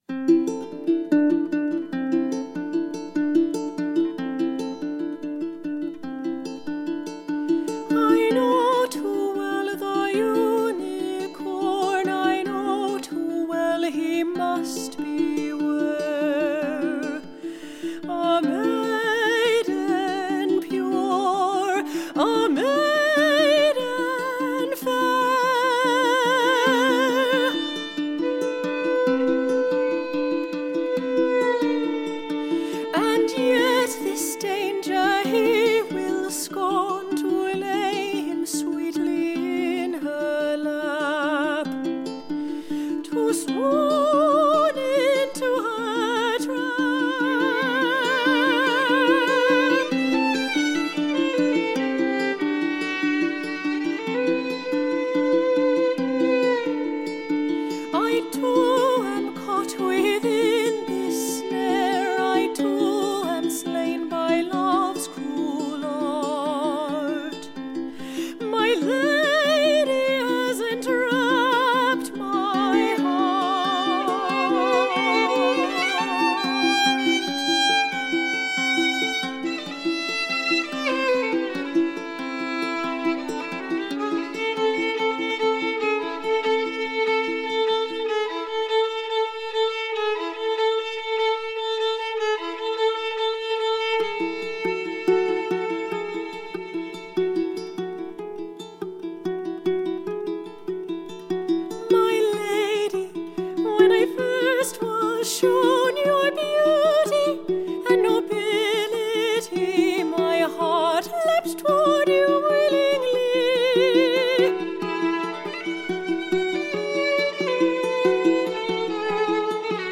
• Genres: Classical, Opera
soprano & violin
soprano & ukulele